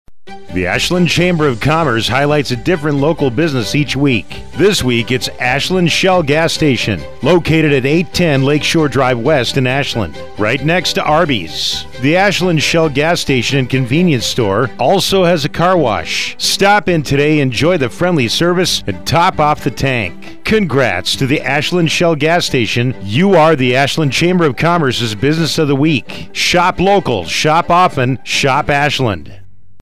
Each week the Ashland Chamber of Commerce highlights a business on Heartland Communications radio station WATW 1400AM. The Chamber draws a name from our membership and the radio station writes a 30-second ad exclusively for that business.